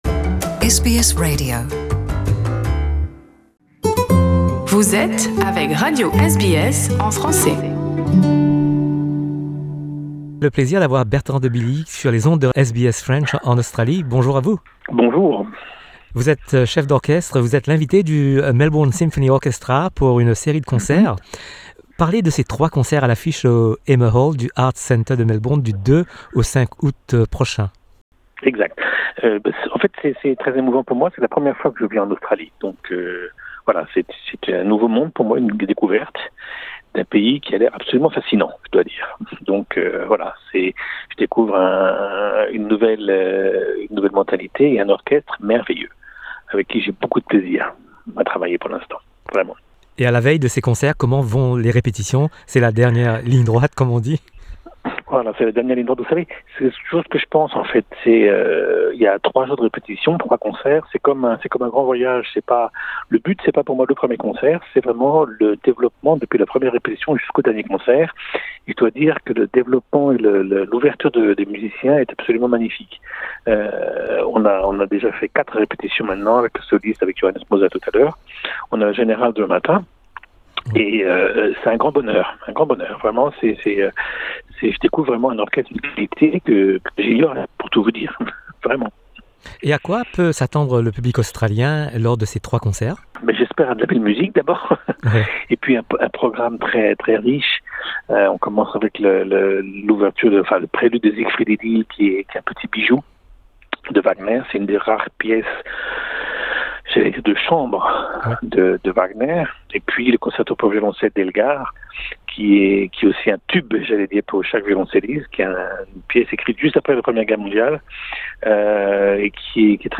Rencontre avec Bertrand de Billy, chef d’orchestre français et l’invité du Melbourne Symphony Orchestra pour une série de concerts au Hamer Hall du Victorian Arts Centre du 2 au 5 août.